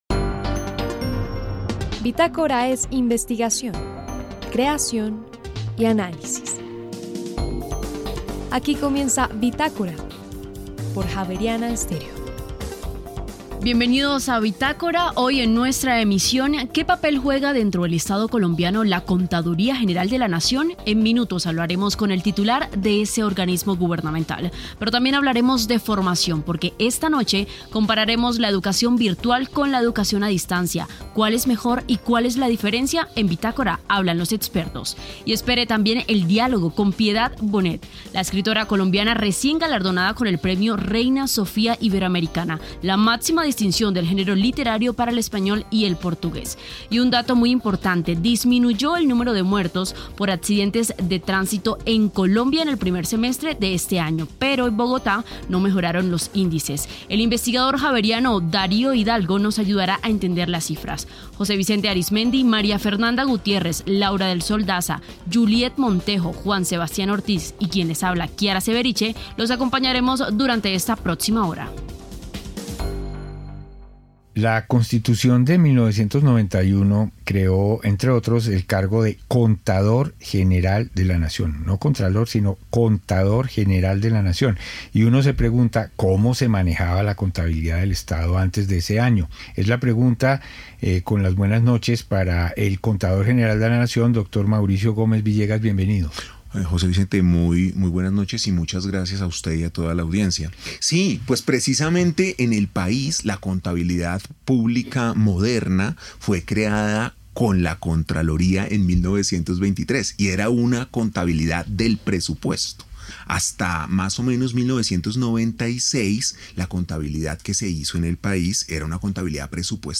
entrevista-u-javeriana